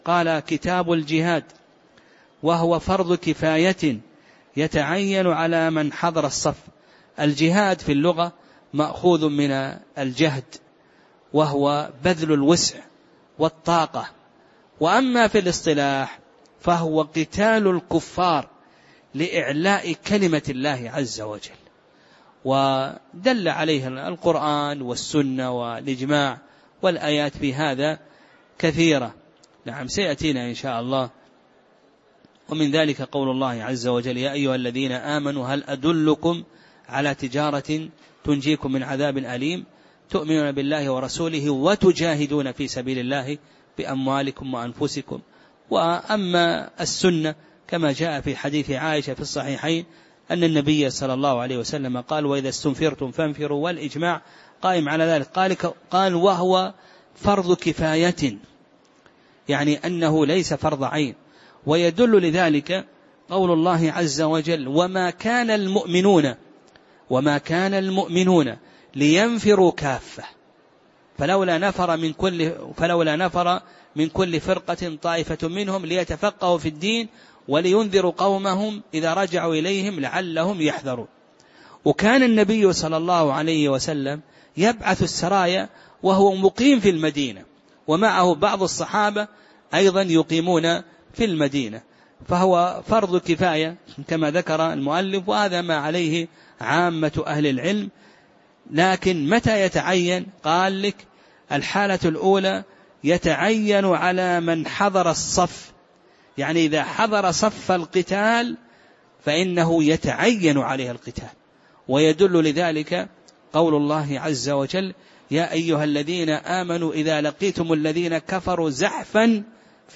تاريخ النشر ٢٣ شوال ١٤٣٩ هـ المكان: المسجد النبوي الشيخ